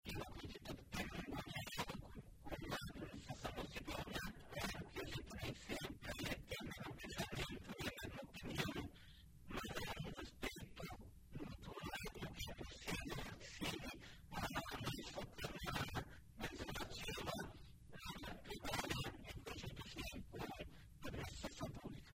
Durante entrevista ontem à noite no programa de Bozano, pela RPI, o presidente do Legislativo, Elton Schwanke, do PDT, disse que dentre as principais matérias aprovadas, de origem do Executivo, destaque para áreas da saúde e educação, como contratação emergencial de médico e professores, ainda habitação, segurança e recuperação de estradas.
(Abaixo, áudio de Elton)